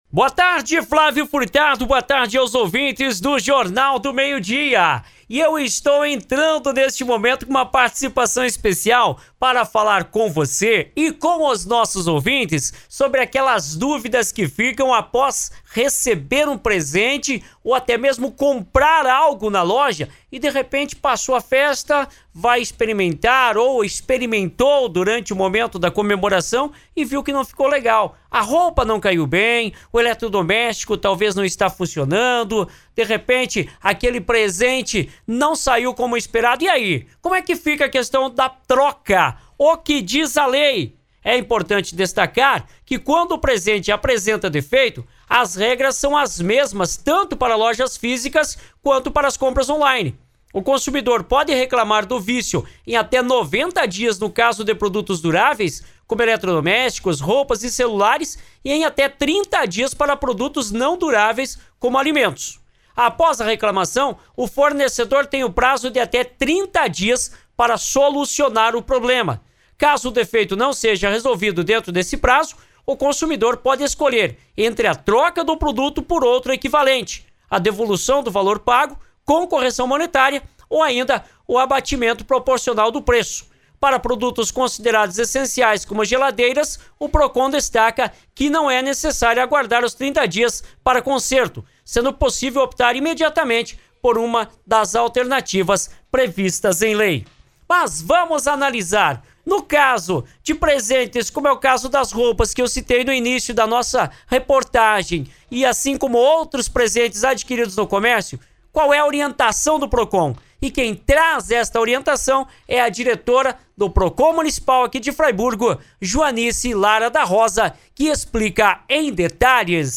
Ouça a matéria com informações importantes e as orientações da diretora do PROCON Municipal de Fraiburgo, Joanice Lara da Rosa.